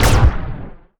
etfx_shoot_energybig.wav